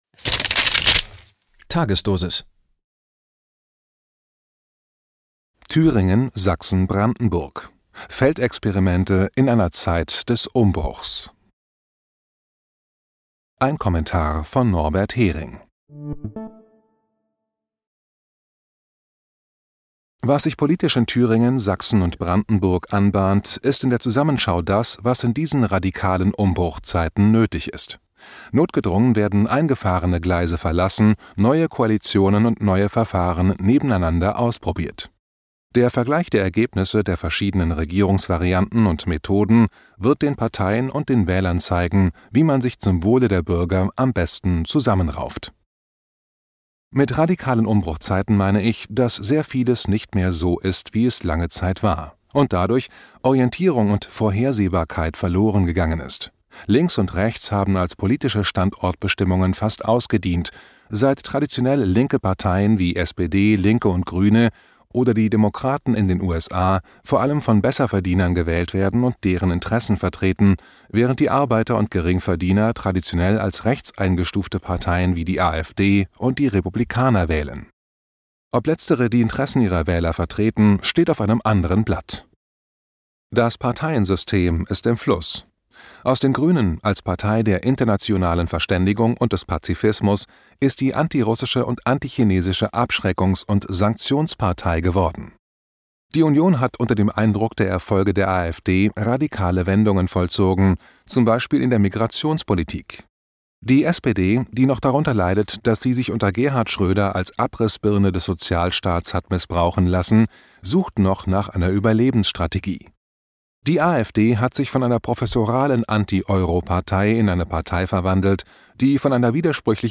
Ein Kommentar von Norbert Häring.